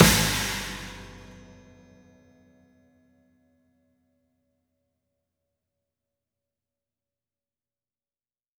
Snare, Crash, Kick OS 02.wav